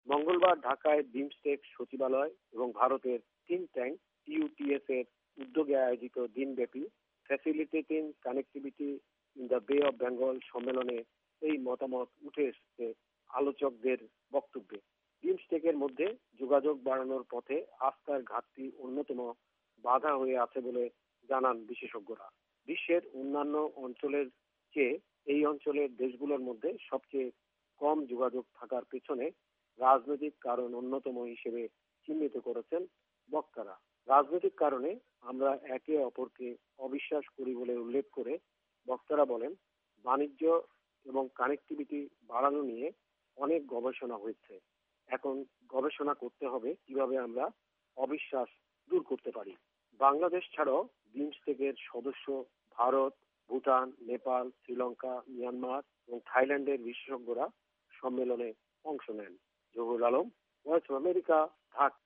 রিপোর্ট বিমস্টেক